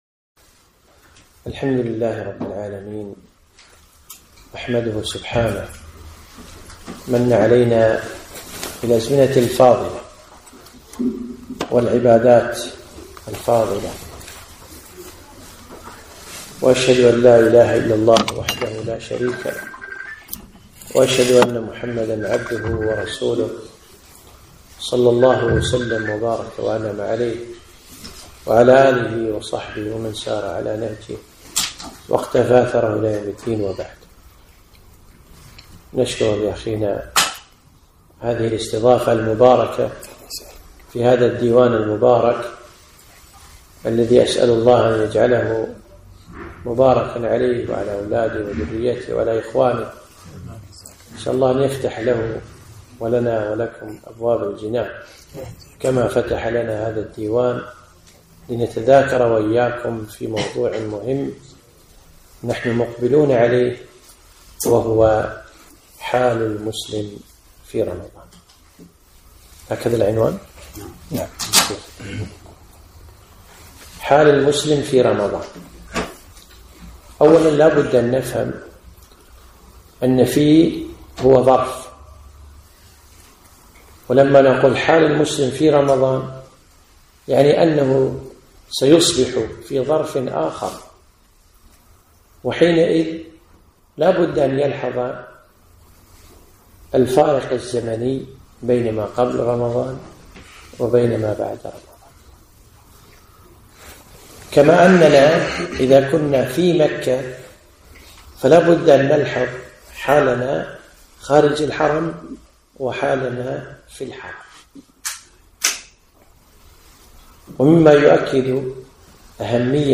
محاضرة - حياة المسلم في رمضان